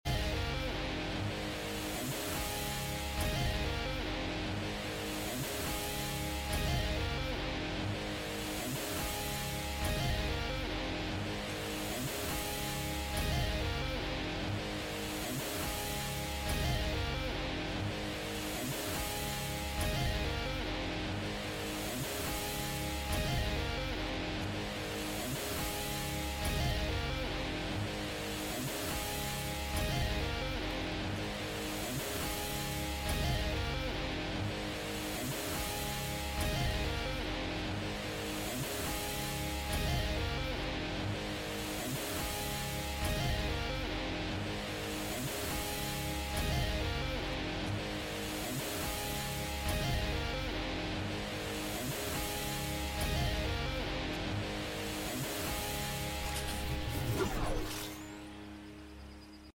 Call of Duty Black Ops 3 2015 XP glitch/level up sound